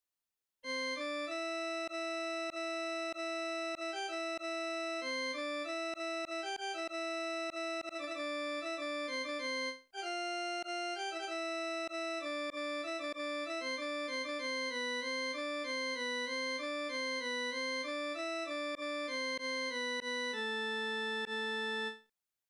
5. kép A TRT lapokból a 227-es számú: Muzaffer Sarısözen urfai gyűjtéséből egy pszalmodizáló dallam